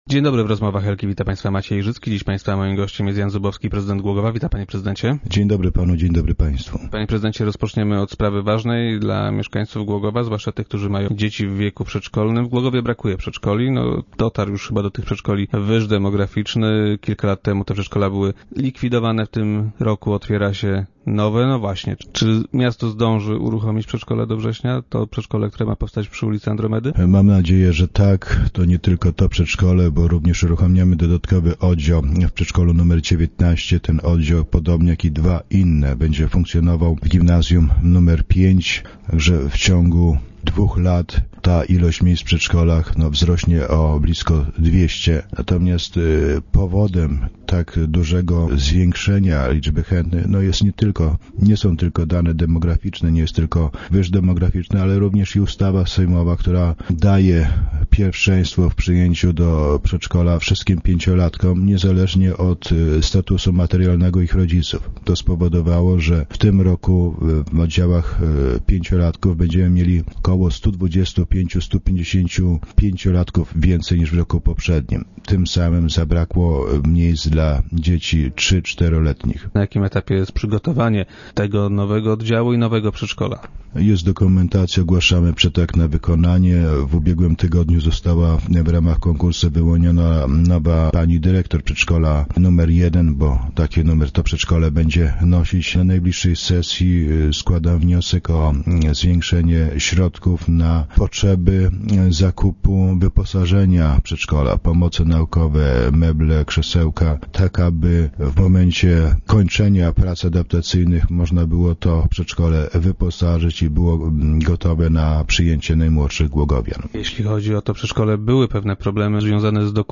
Od września uruchomiona ma zostać nowa placówka na osiedlu Kopernika. Jak powiedział prezydent Jan Zubowski w dzisiejszych Rozmowach Elki, termin zostanie dotrzymany.